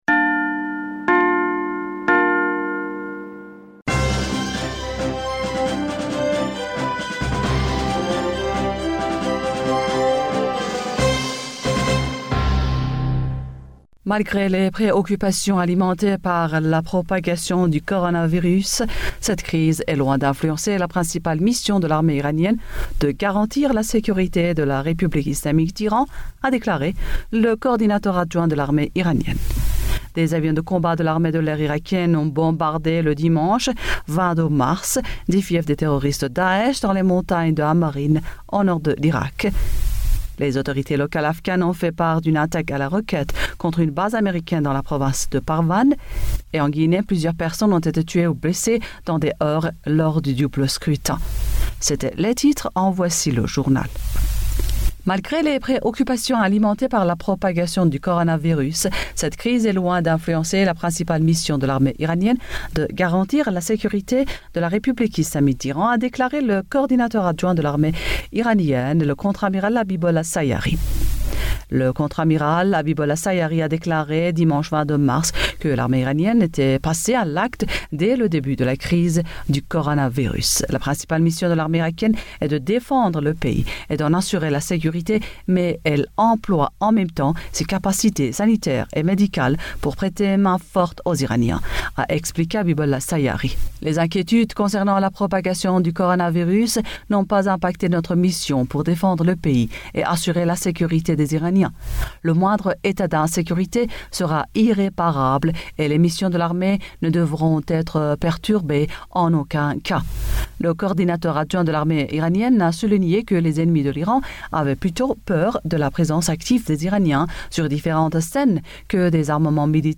Bulletin d'information du 23 mars 2020